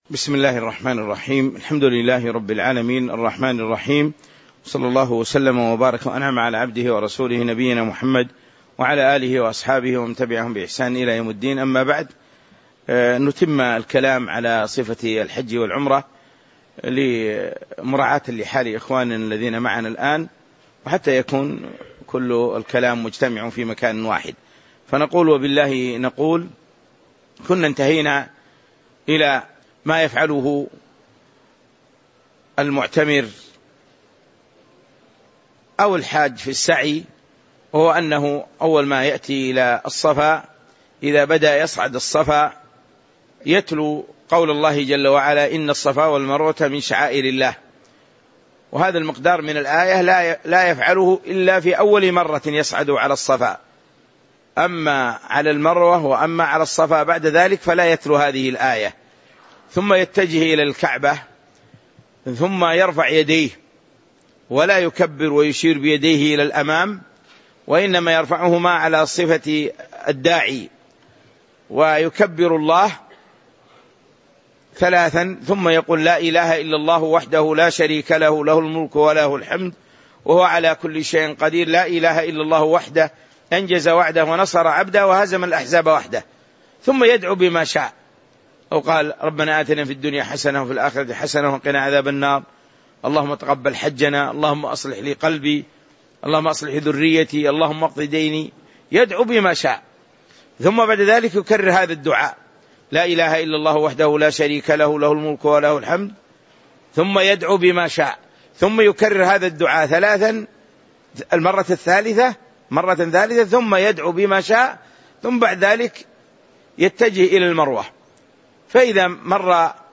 تاريخ النشر ٢٠ ذو القعدة ١٤٤٦ هـ المكان: المسجد النبوي الشيخ